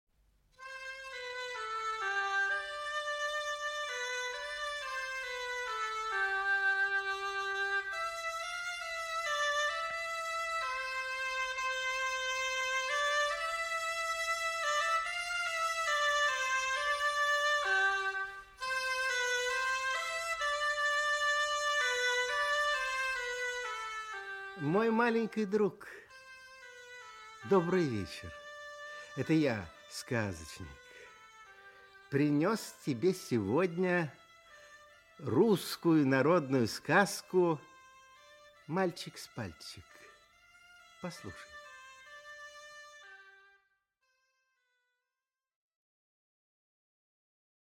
Аудиокнига Мальчик с пальчик | Библиотека аудиокниг
Aудиокнига Мальчик с пальчик Автор Народное творчество Читает аудиокнигу Николай Литвинов.